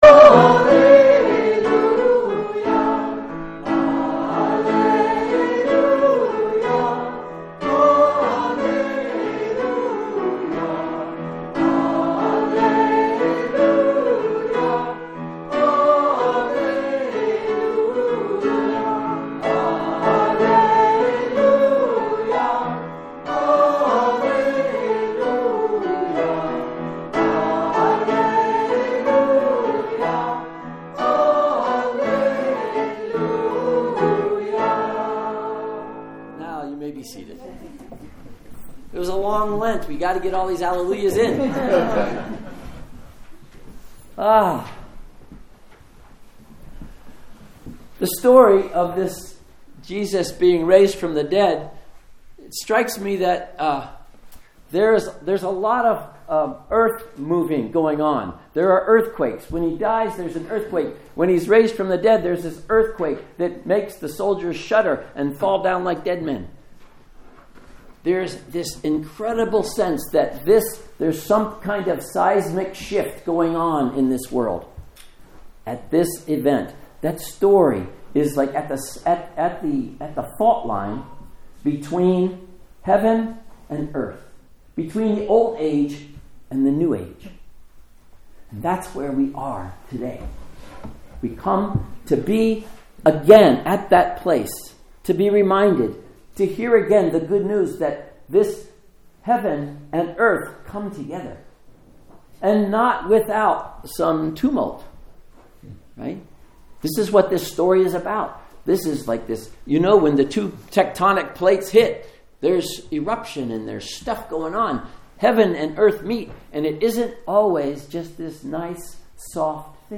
Sermons | Lake Chelan Lutheran Church
Easter Day Service